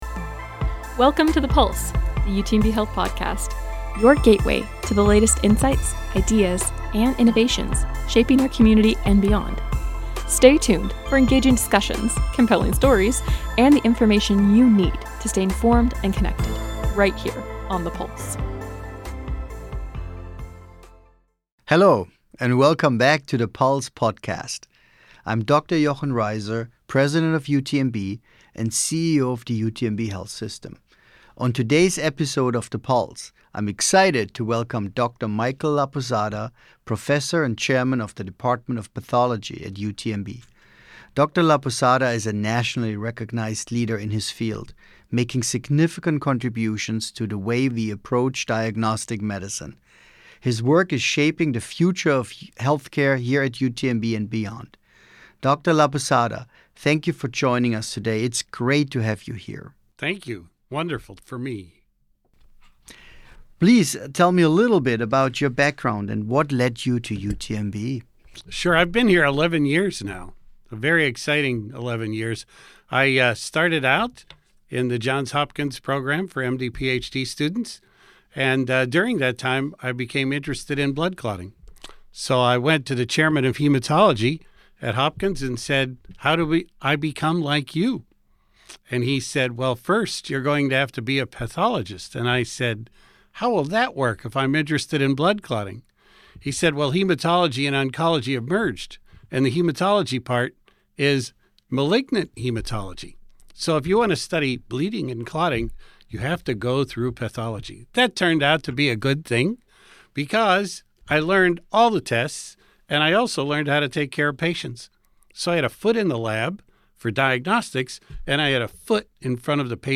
two men at table with microphones on table